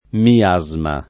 vai all'elenco alfabetico delle voci ingrandisci il carattere 100% rimpicciolisci il carattere stampa invia tramite posta elettronica codividi su Facebook miasma [ mi- #@ ma ] (raro miasmo [ mi - #@ mo ]) s. m.; pl. ‑smi